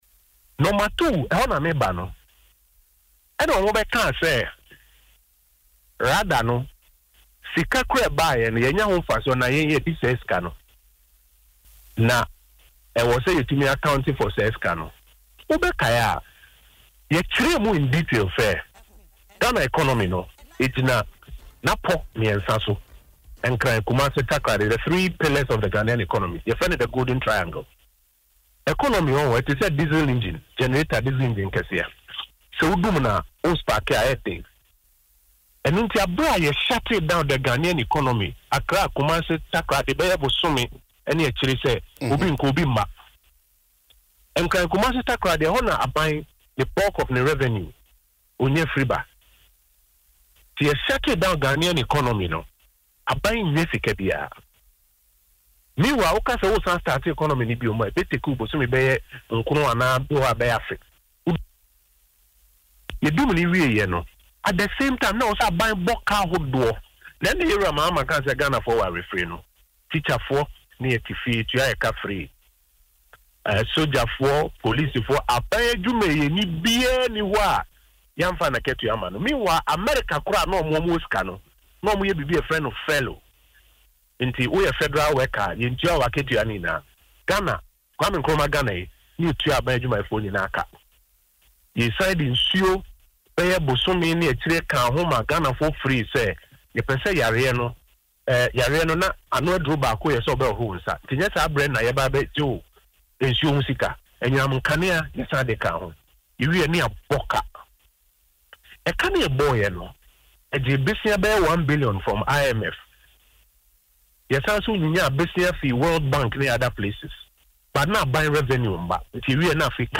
Speaking on Adom FM’s Dwaso Nsem morning show, Mr. Oppong Nkrumah said: